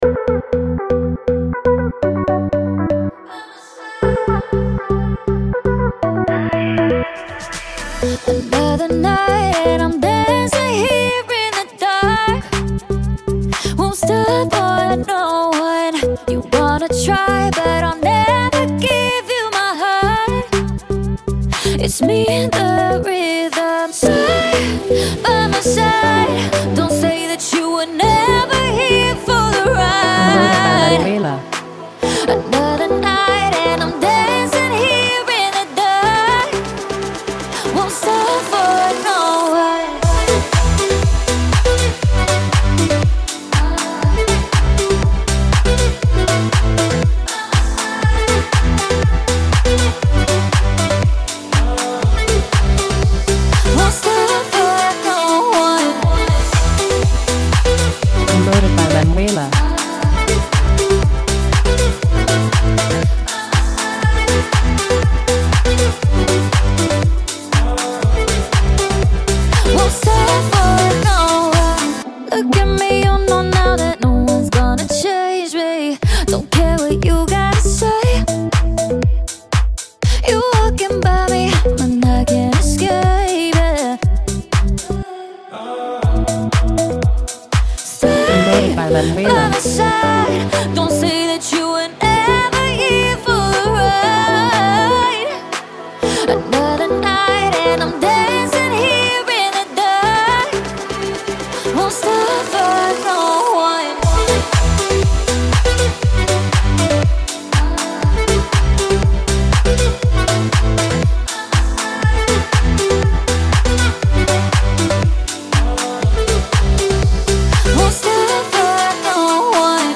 Un duo énergique made in Miami!
Radio Edit